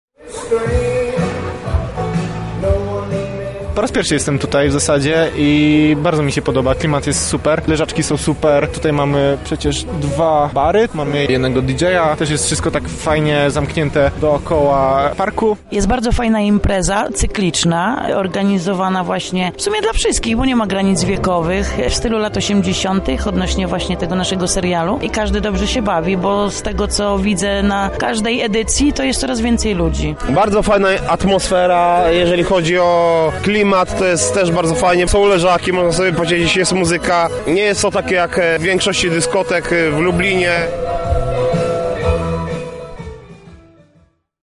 W sobotę chętni uczestniczyli w pierwszej wakacyjnej odsłonie imprezy The 80’s Party: Stranger Things Night. Tego wieczoru możliwa była zabawa przy dźwiękach muzyki z lat 80-tych w stylistyce new wave i synthpop.